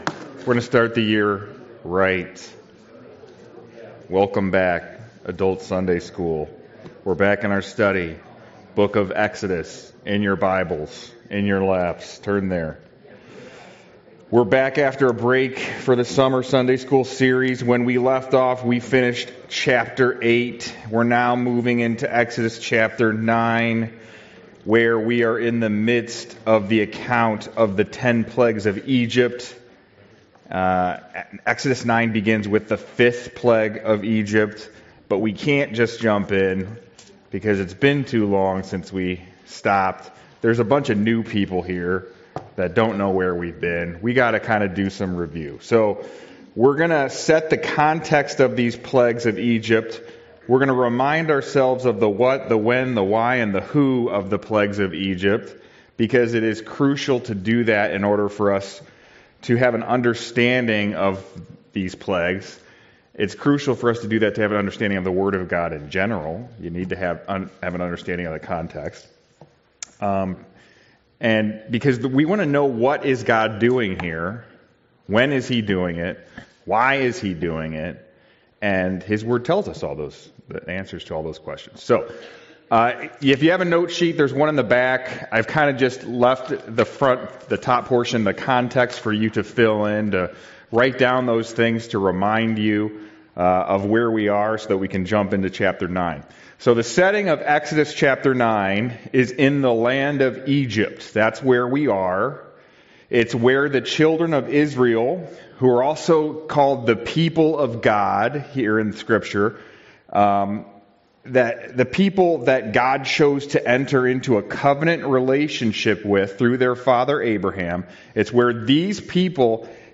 Passage: Exodus 9 Service Type: Sunday School « Sorrento